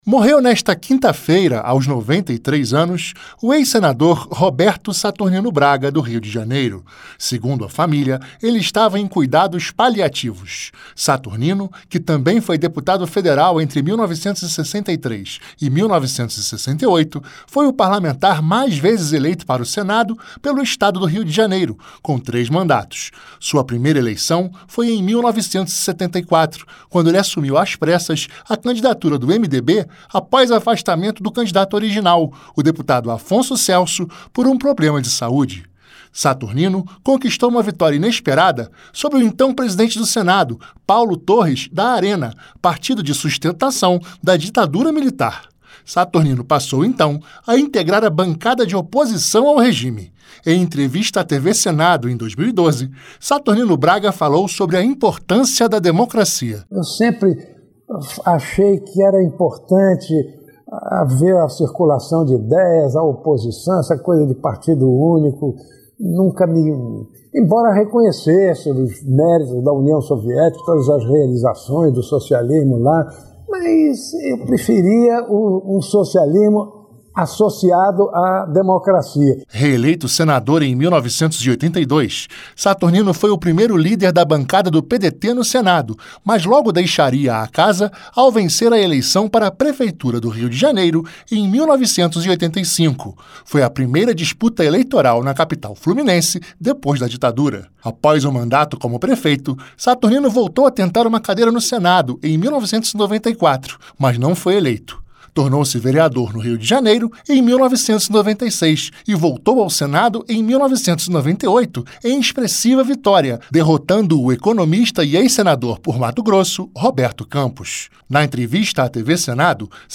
Nota de falecimento